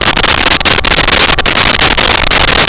Sfx Parts Rack Loop
sfx_parts_rack_loop.wav